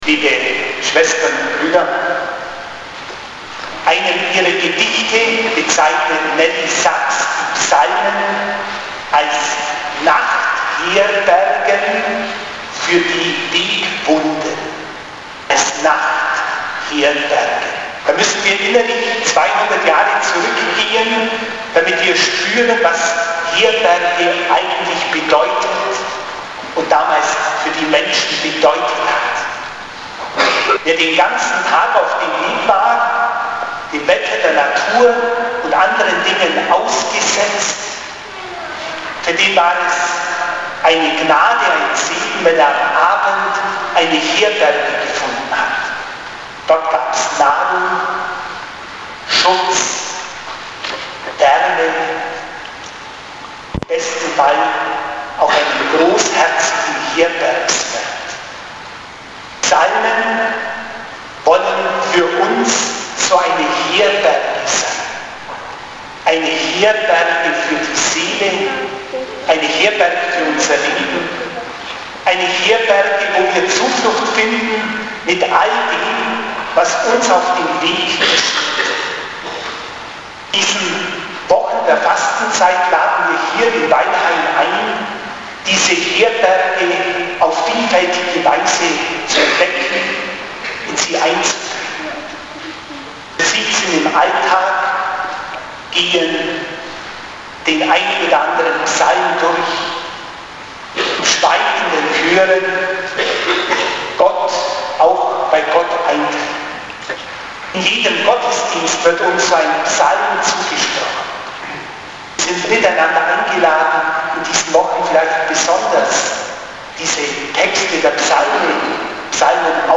Ansprachen zu den Gottesdiensten: